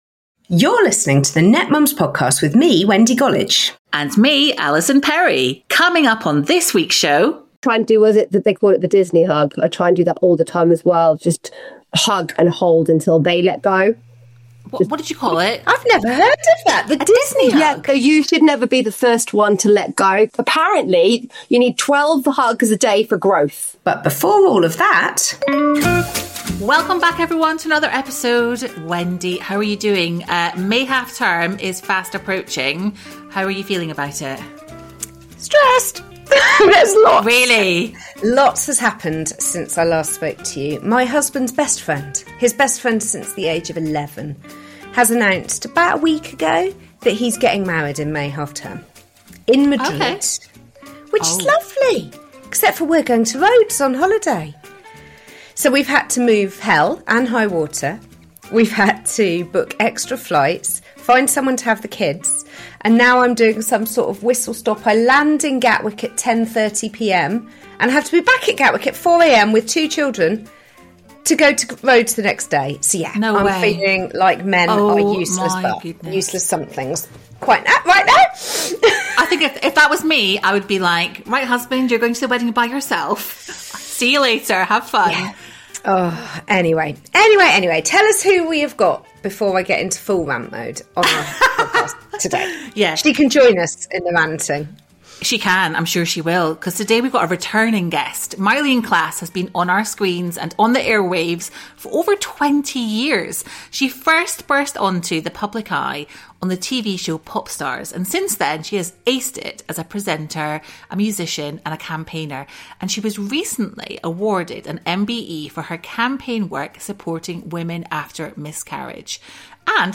Myleene shares her candid experiences of parenting, the challenges of raising teenagers, and the joys of embracing the chaos of family life. The conversation covers: